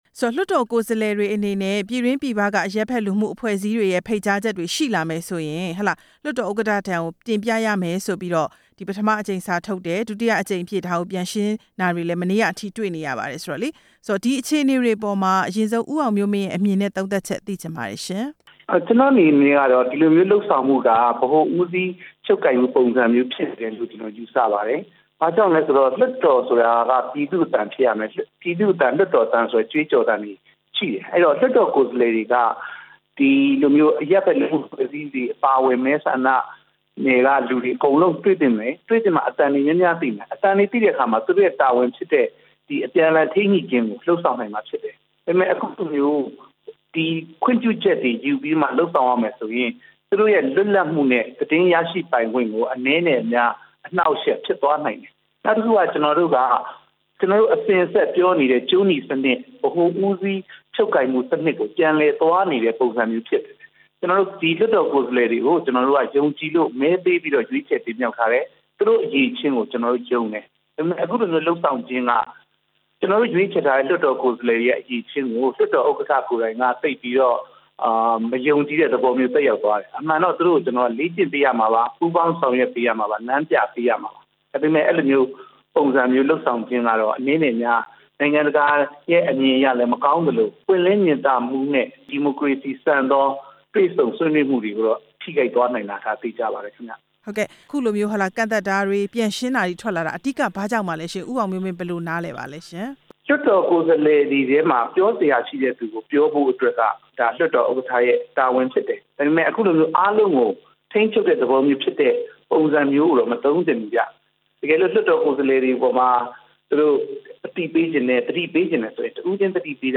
CSO တွေရဲ့ ဖိတ်ကြားချက်ကို လွှွတ်တော်ဥက္ကဌထံ တင်ပြရမယ့်ကိစ္စ မေးမြန်းချက်